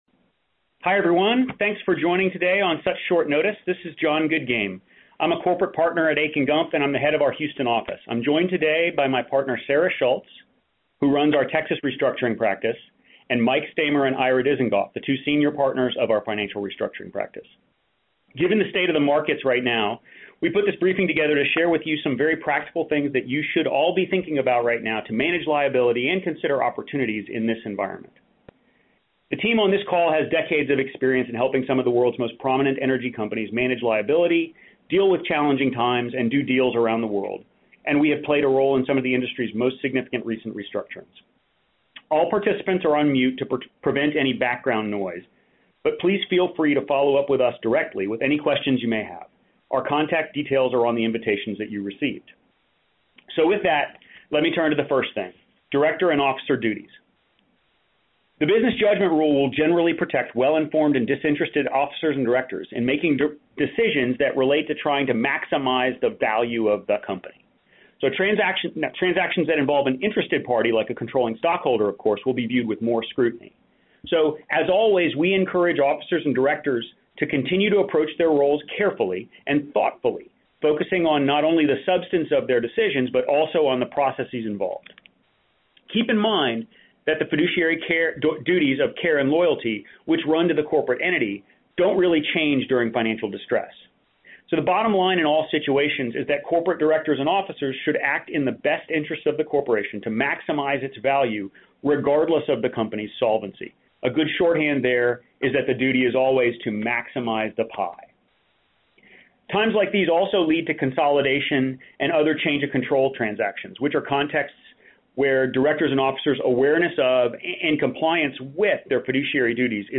Akin Gump Hosts “Managing the New Reality” Phone Briefing